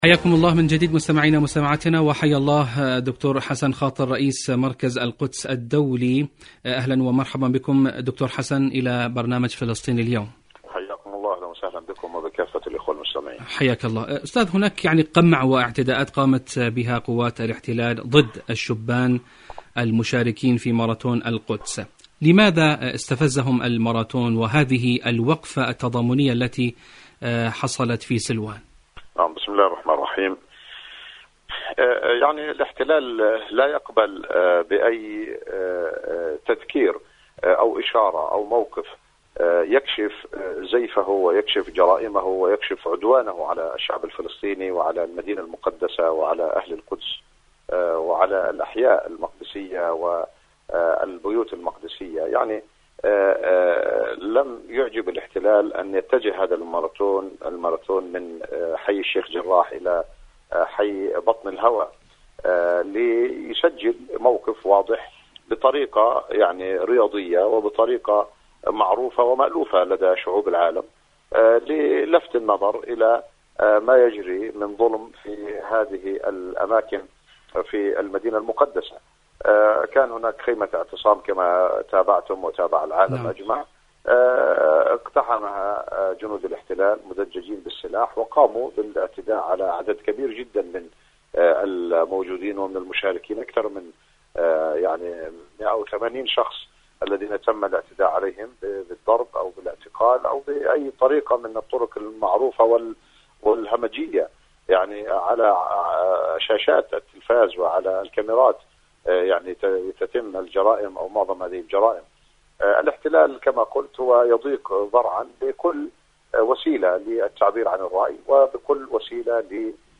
إجماع فلسطيني للقدس وماراثون التصدي.. مقابلة